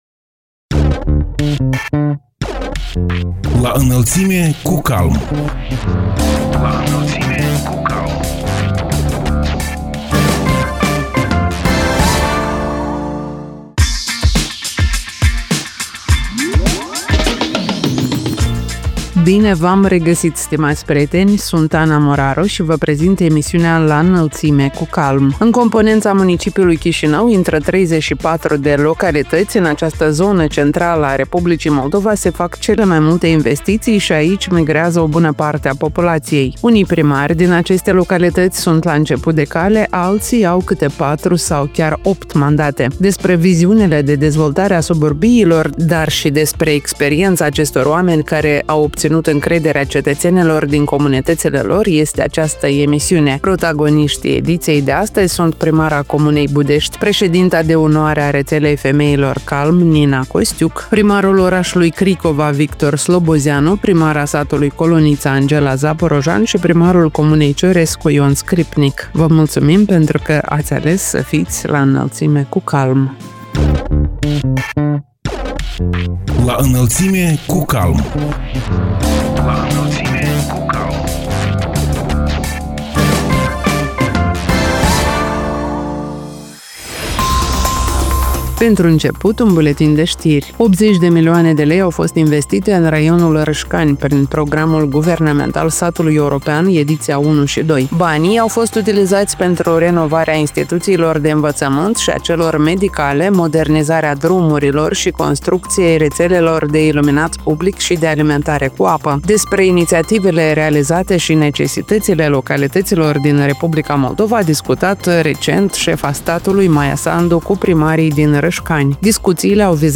Despre viziunile de dezvoltare a suburbiilor, dar și despre experiența acestor oameni care au obținut încrederea cetățenilor din comunitățile lor este emisiunea „La Înălțime cu CALM”. Protagoniștii ediției sunt primara comunei Budești, președinta de onoare a Rețelei Femeilor CALM, Nina Costiuc; primarul orașului Cricova, Victor Slobozianu; primara satului Colonița, Angela Zaporojan și primarul comunei Ciorescu, Ion Scripnic.